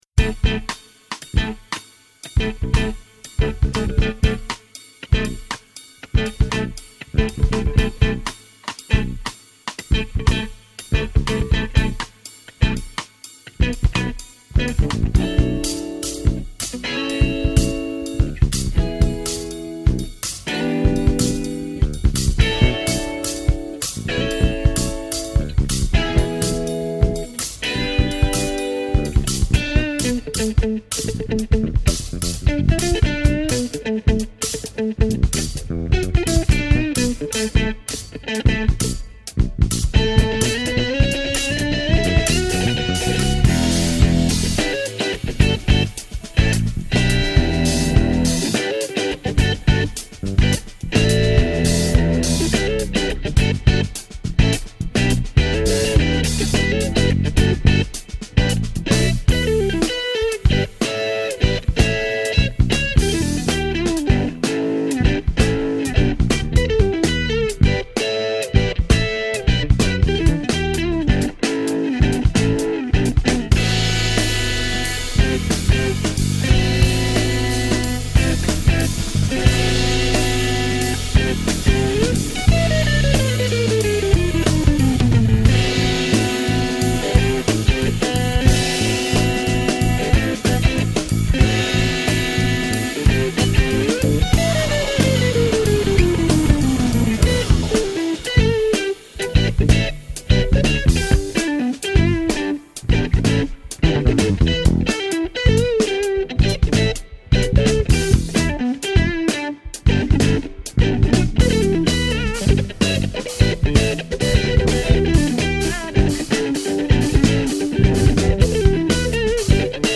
power-fusion trio